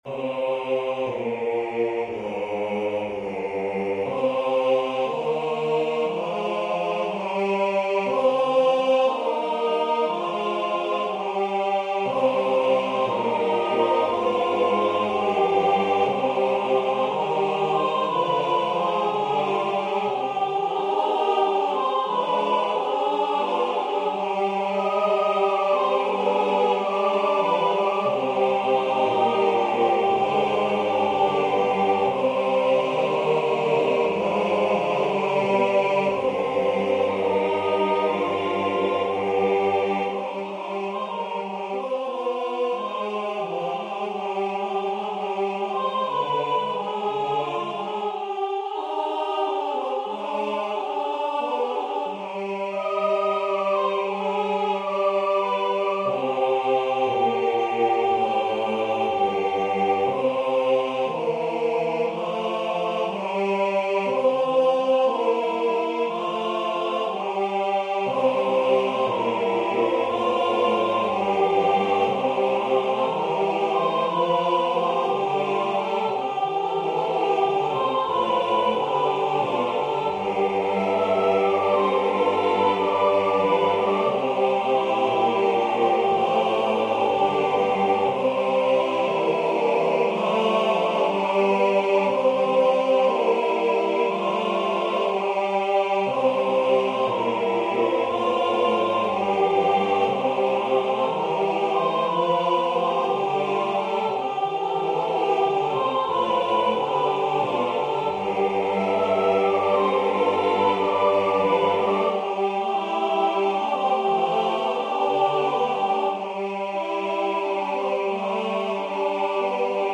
koorgeluid of
Zutphen Kanon-koor geluid.mp3